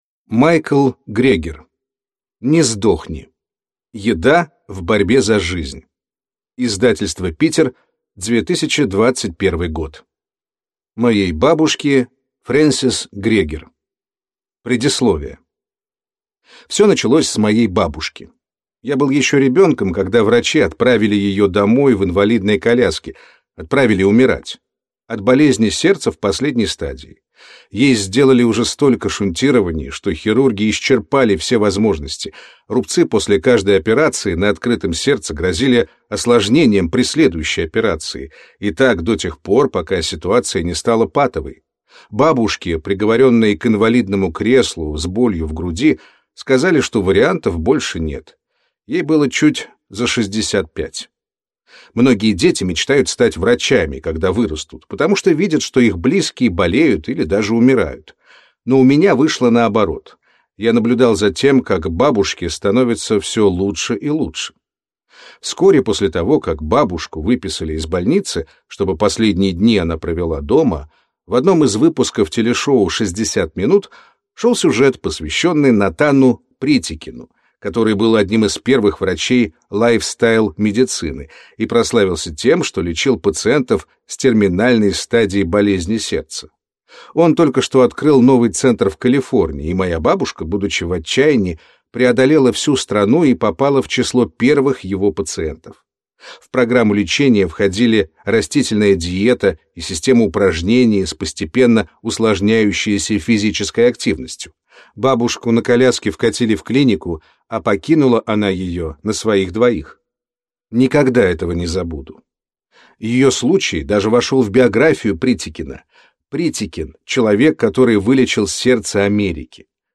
Аудиокнига Не сдохни! Еда в борьбе за жизнь. Часть 1 | Библиотека аудиокниг